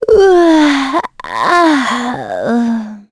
Gremory-Vox_Sigh_b.wav